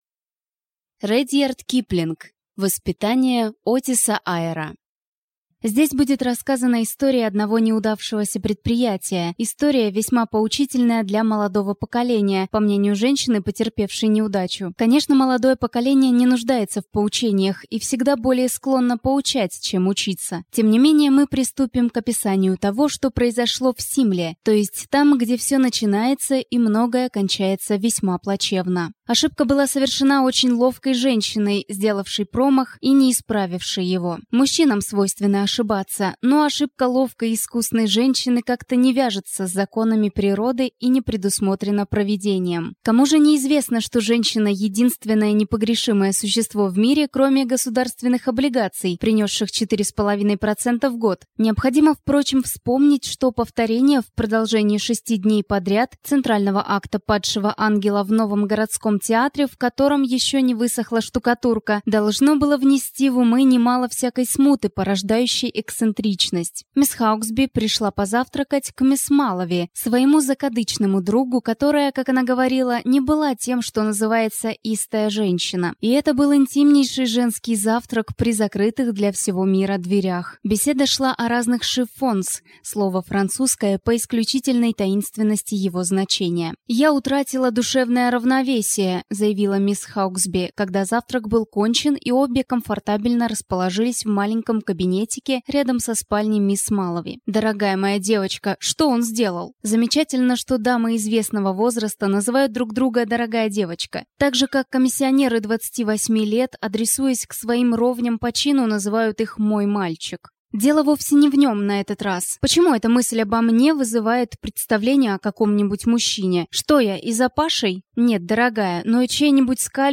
Аудиокнига Воспитание Отиса Айира | Библиотека аудиокниг